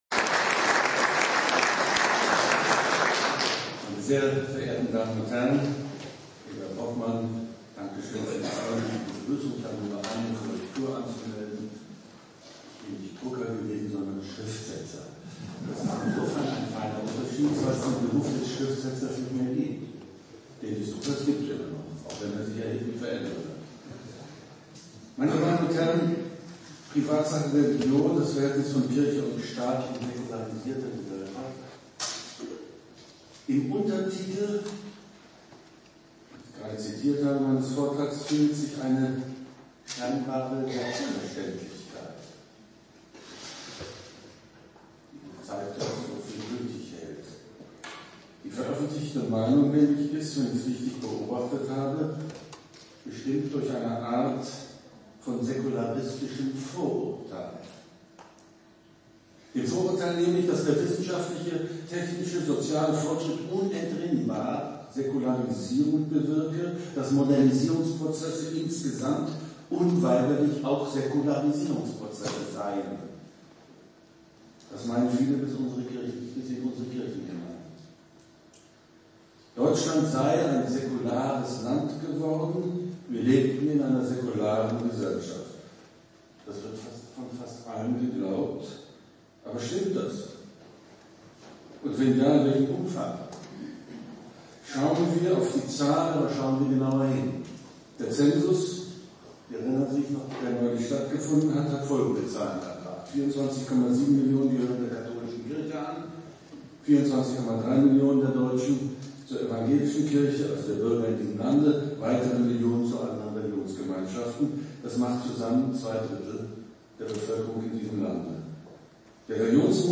KEB-Osnabrueck_Privatsache_Religion_Vortrag_von_Dr_Wolfgang_Thierse.mp3